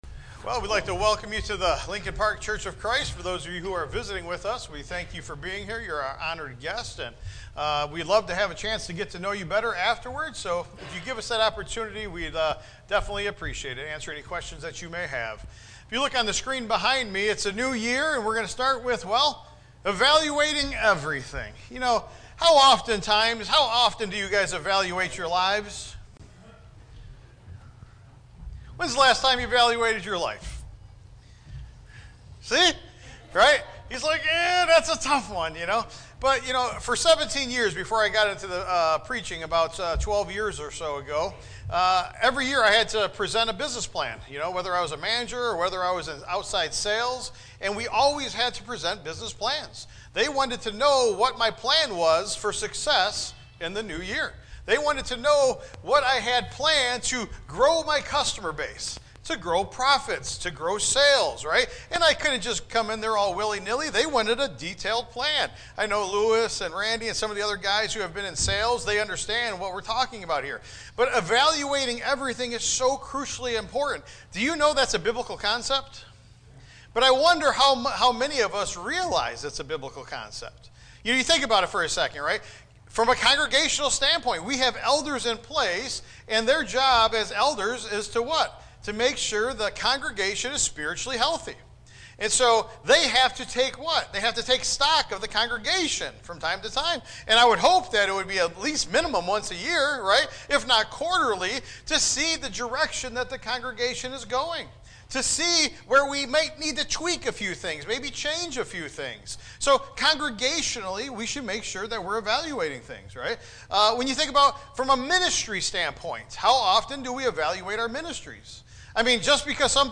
Tagged with sermon Audio (MP3) 13 MB Previous The Stone Next The Blind Man